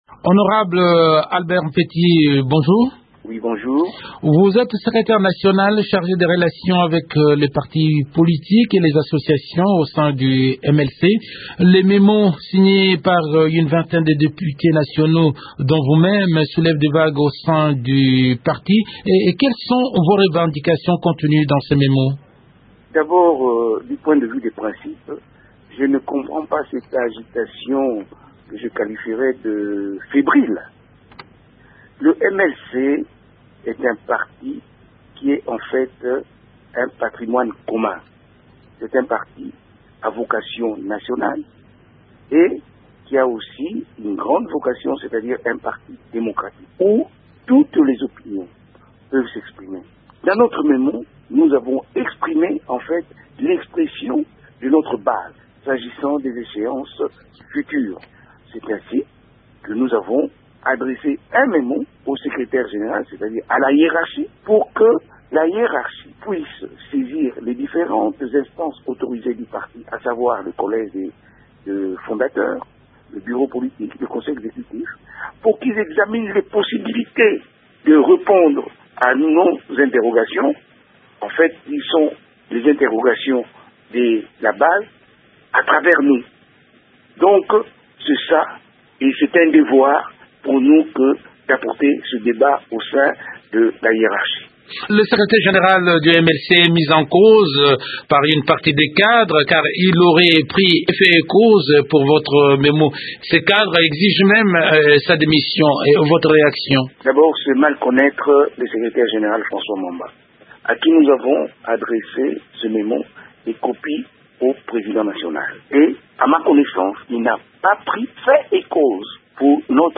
Cet entretien a été réalisé avant la mesure de la déchéance de François Mwamba prise lundi par le collège de s fondateurs.